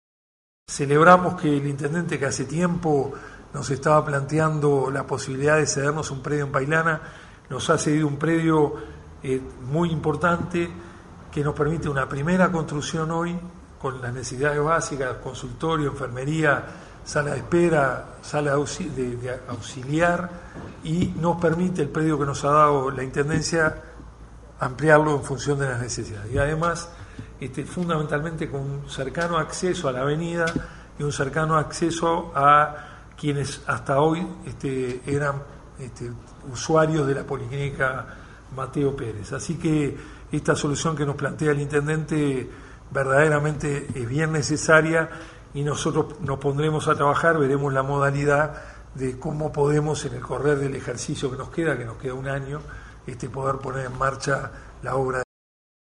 Declaraciones de Carámbula;
Carambula.mp3